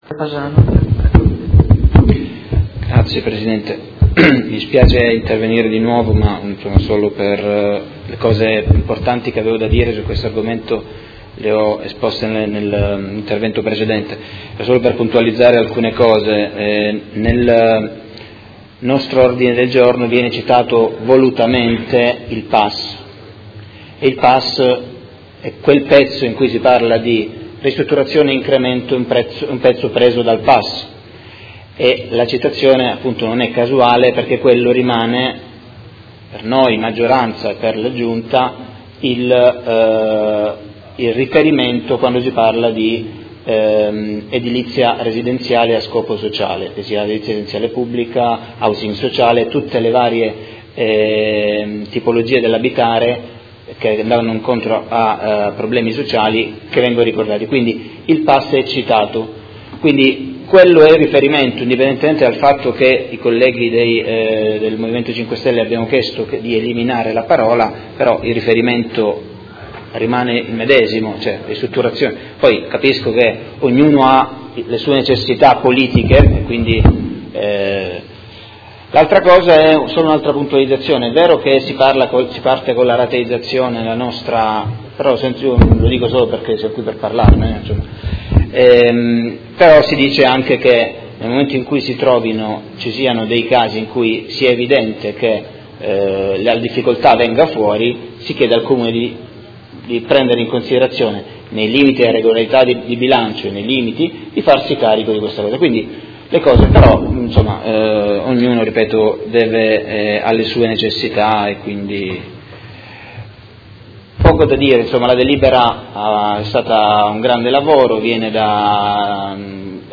Tommaso Fasano — Sito Audio Consiglio Comunale
Seduta del 5/04/2018. Dichiarazioni di voto su proposta di deliberazione: Approvazione Regolamento per la definizione delle modalità di calcolo e di applicazione dei canoni di locazione degli alloggi di edilizia residenziale pubblica con decorrenza 1 ottobre 2017, Ordini del Giorno ed emendamento